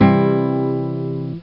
Ac Guitar Maj Sound Effect
Download a high-quality ac guitar maj sound effect.
ac-guitar-maj.mp3